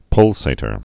(pŭlsātər, pŭl-sā-)